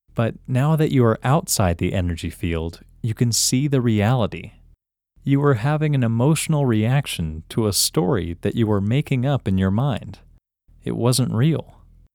OUT – English Male 31